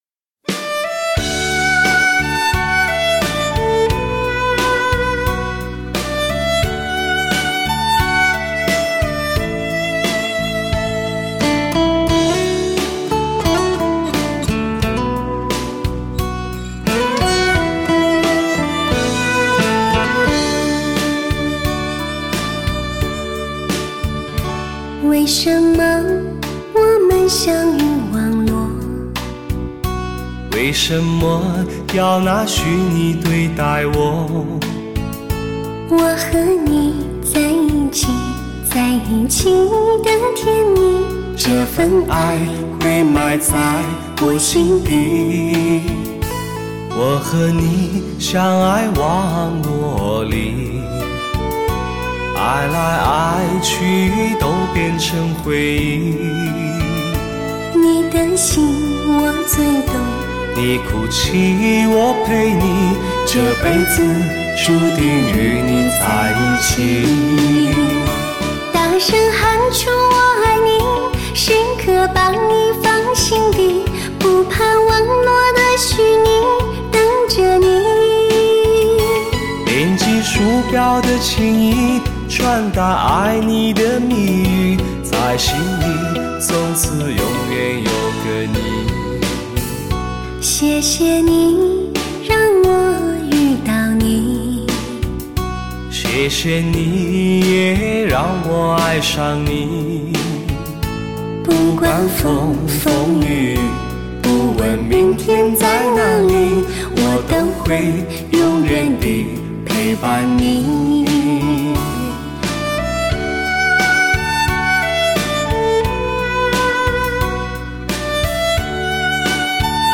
感动心灵的吟唱，勾起你心灵最深的感触，传奇之声，非同凡响，纯情演绎，细腻非凡，通透中的绝美无比。